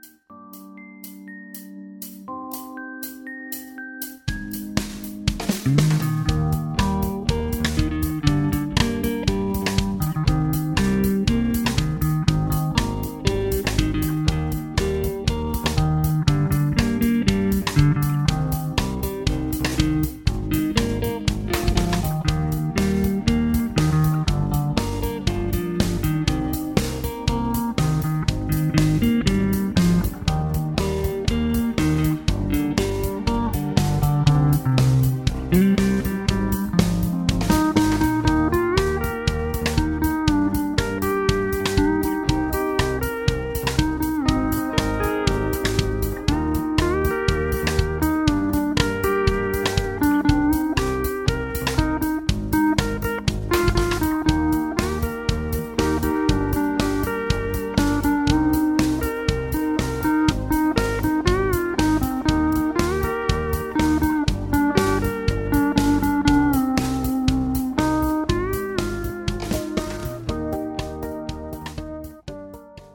(High Key)
Singing Calls